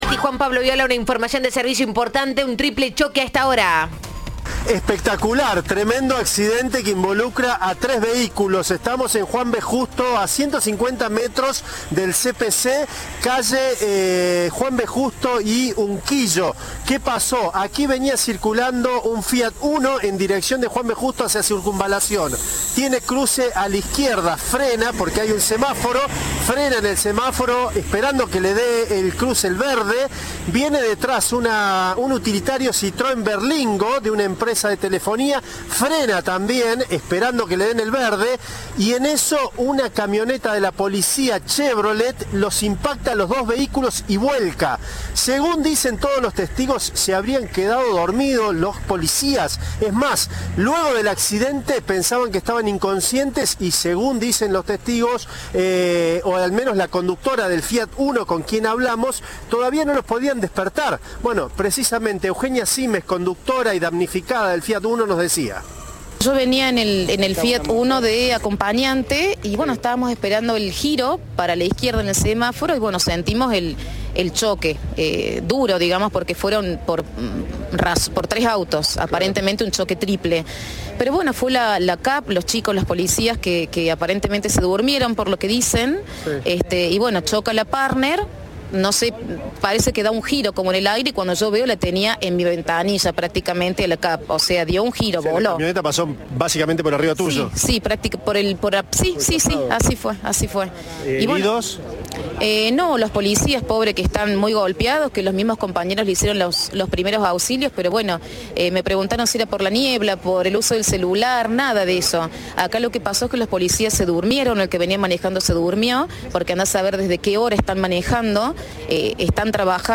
El móvil embistió a dos vehículos La acompañante de uno de los autos impactados contó la secuencia a Cadena 3.
Informe